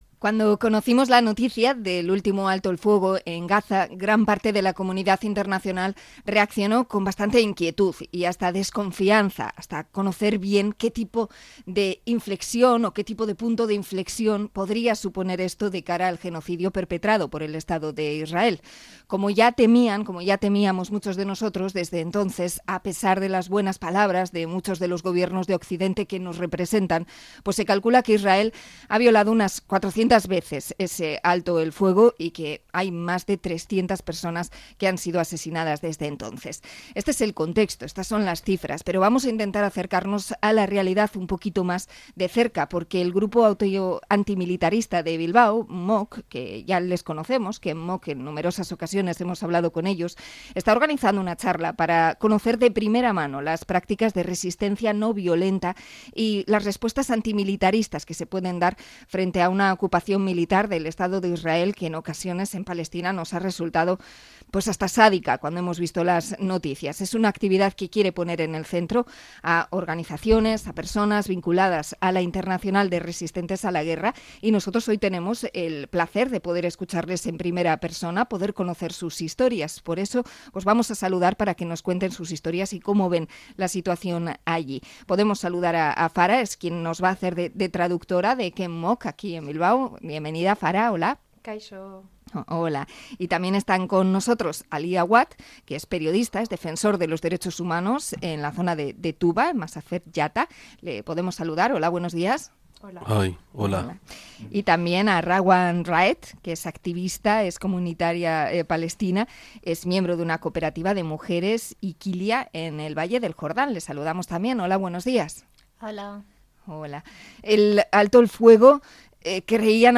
Entrevista a activistas palestinos